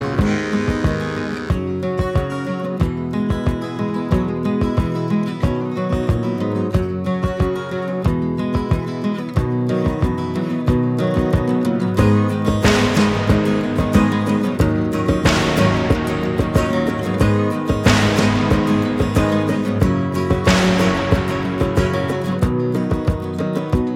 No Harmony Pop (1960s) 5:08 Buy £1.50